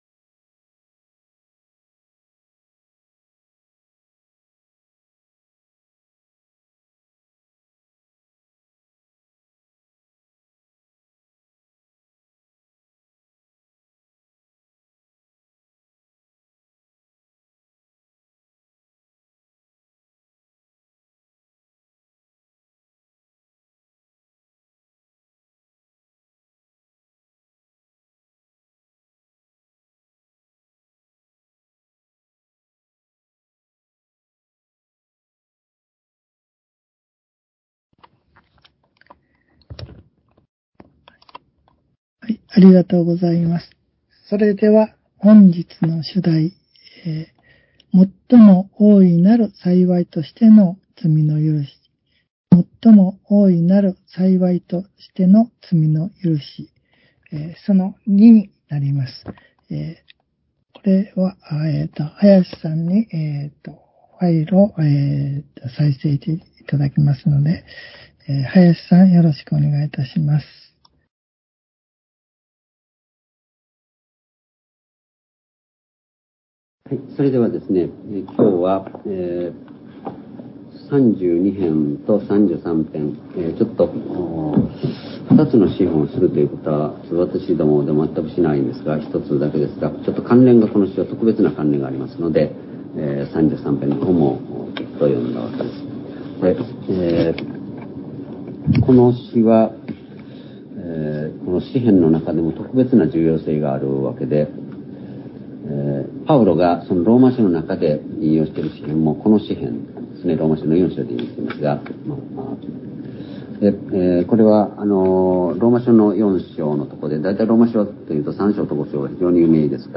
（主日・夕拝）礼拝日時 ２０２２年１２月２０日（夕拝） （録音による集会）） 聖書講話箇所 「最も大いなる幸いとしての罪の赦し」（その２） ３２編（33編）より ※視聴できない場合は をクリックしてください。